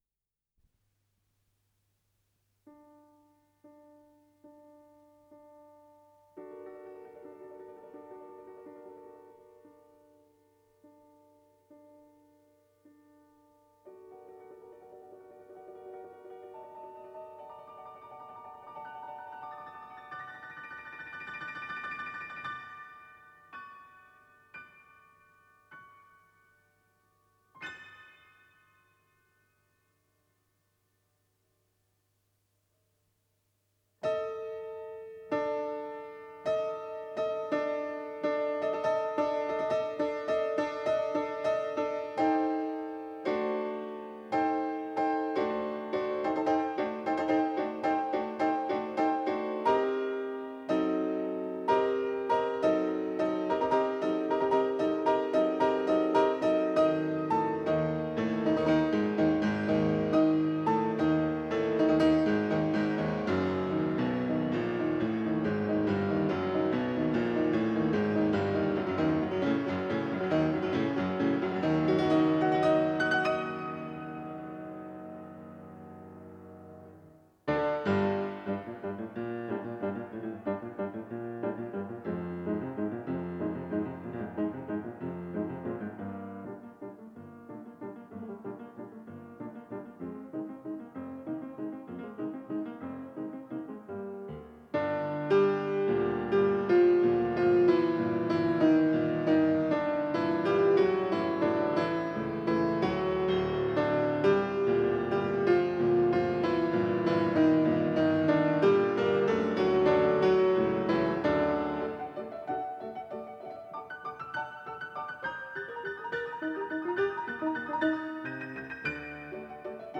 фортепиано
соль минор